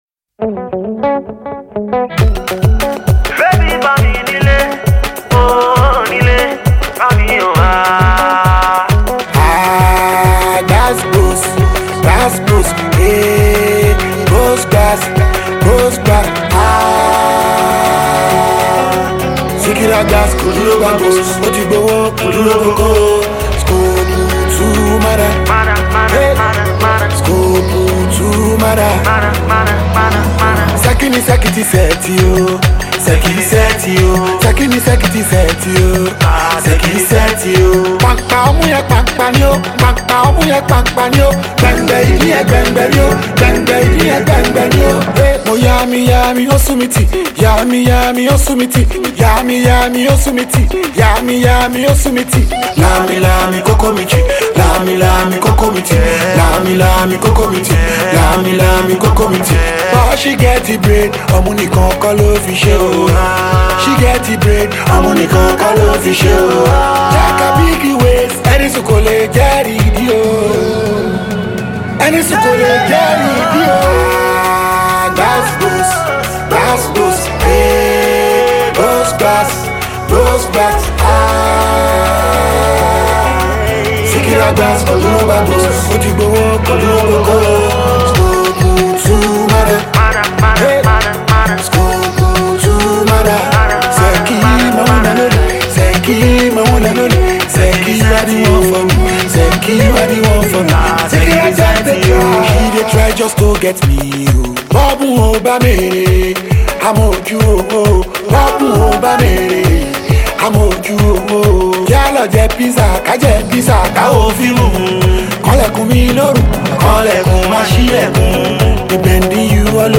The groovy track
strings